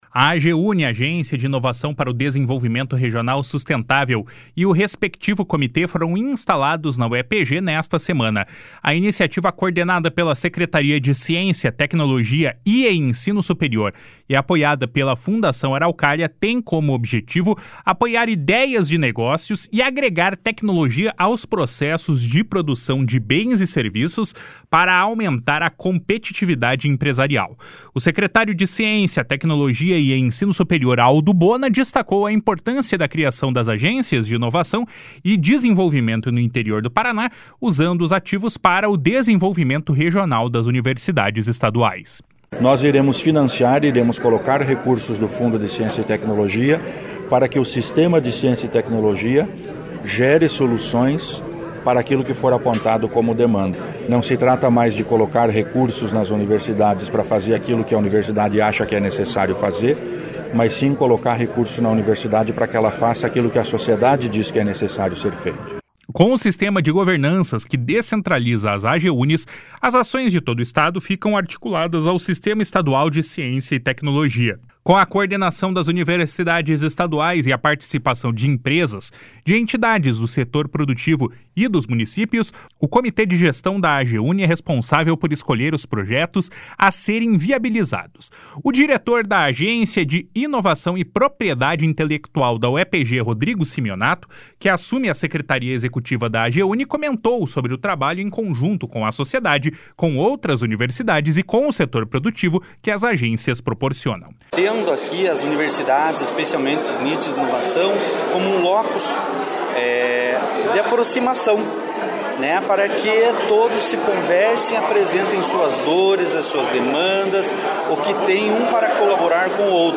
// SONORA ALDO BONA //